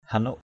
hanuk.mp3